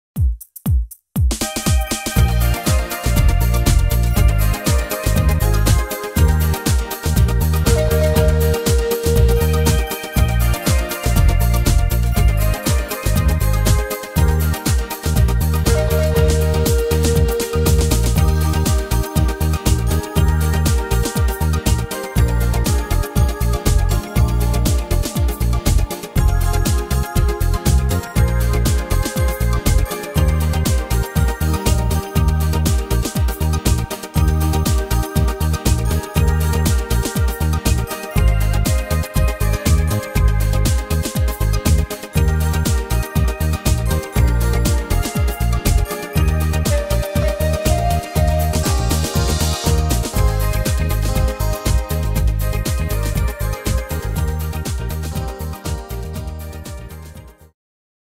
Tempo: 120 / Tonart: Eb-Dur